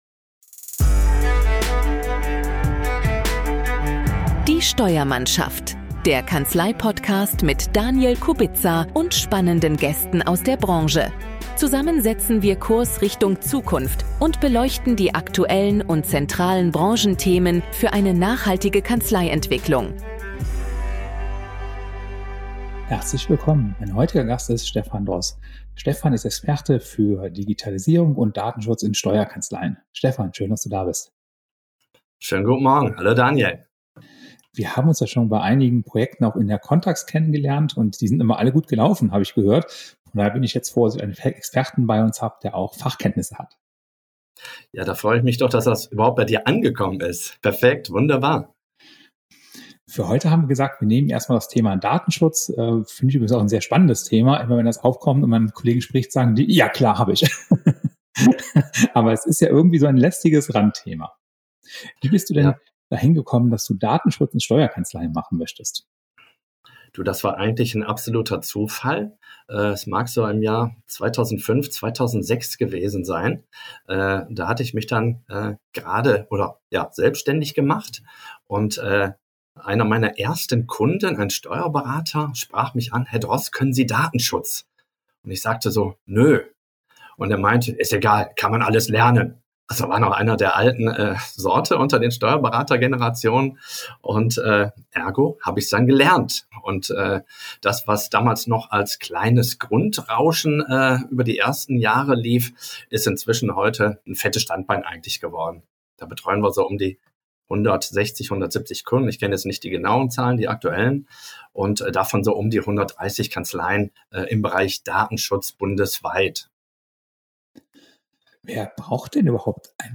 STUDIOGAST